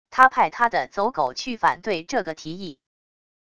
他派他的走狗去反对这个提议wav音频生成系统WAV Audio Player